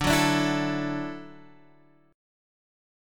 Dadd9 chord